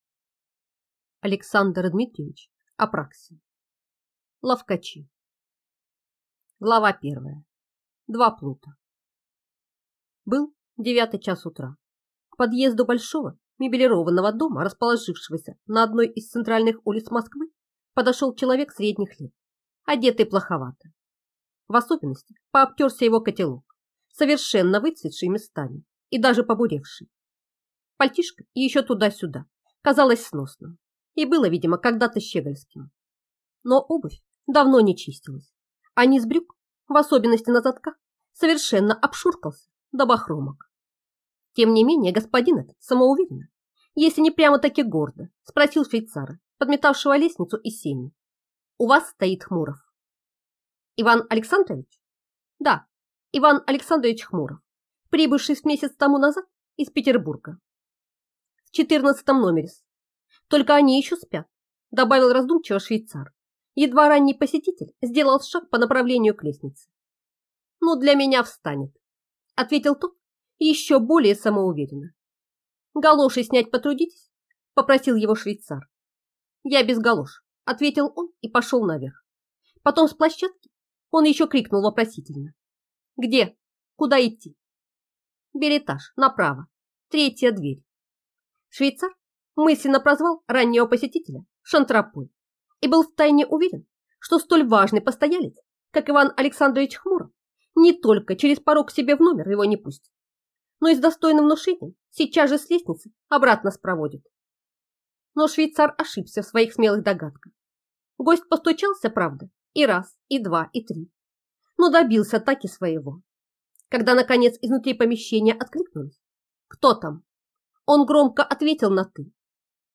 Аудиокнига Ловкачи | Библиотека аудиокниг